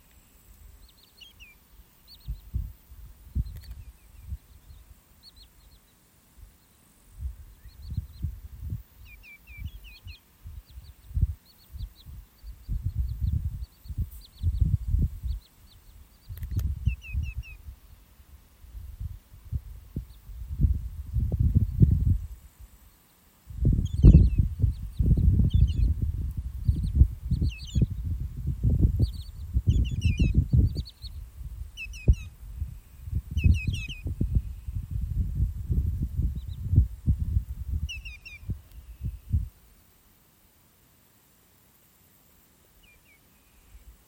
Птицы -> Kулики ->
большой улит, Tringa nebularia
СтатусСлышен голос, крики